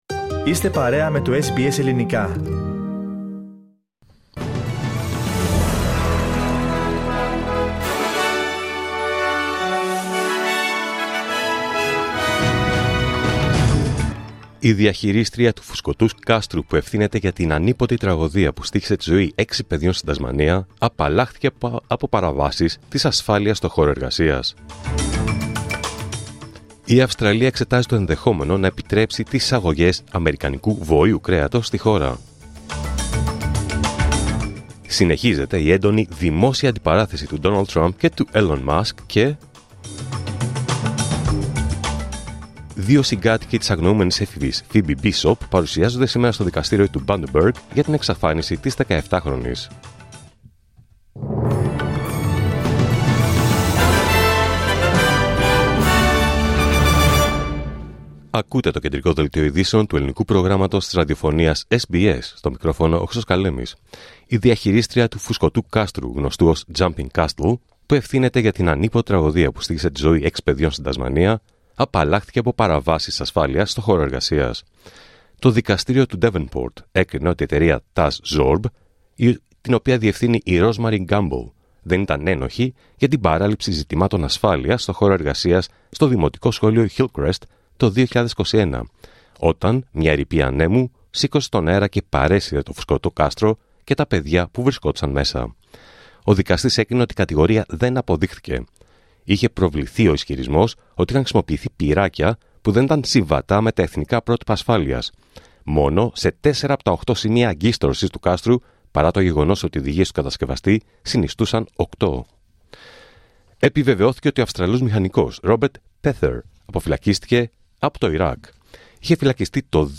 Δελτίο Ειδήσεων Παρασκευή 6 Ιουνίου 2025